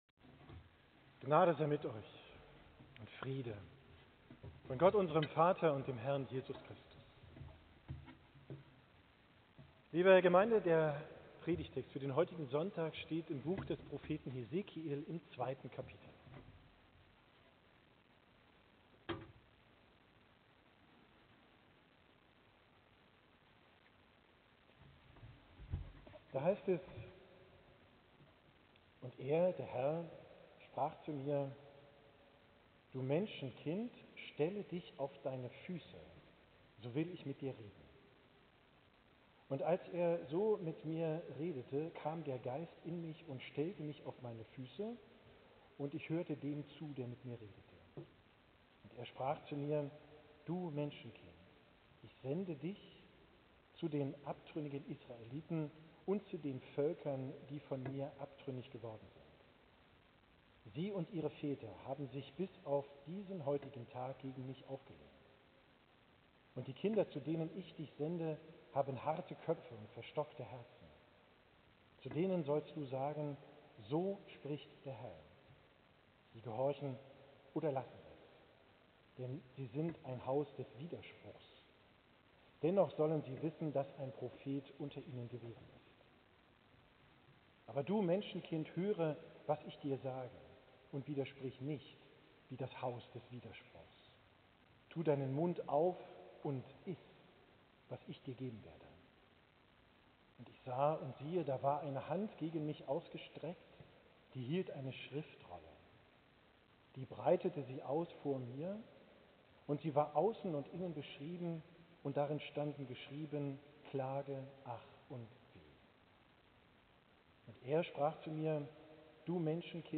Predigt vom Sonntag Sexagesima, 8.